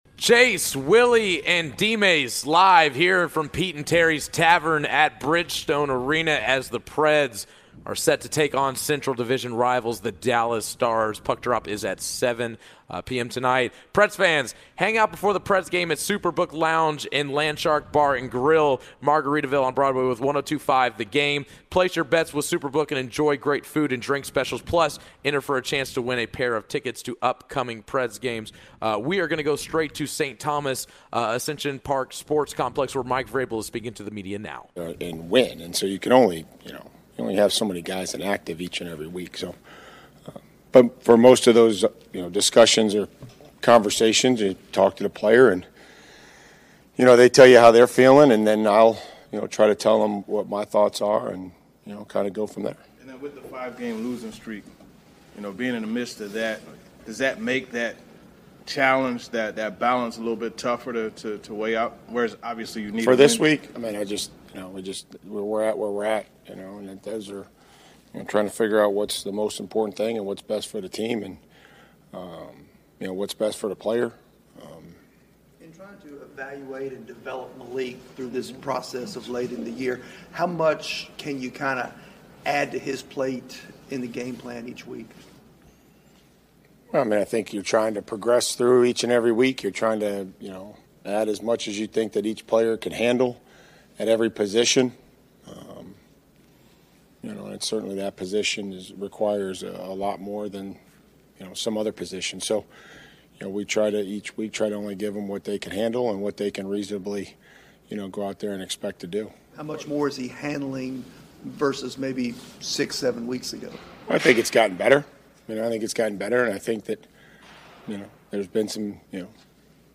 Hour 3: Mike Vrabel presser (12-27-22)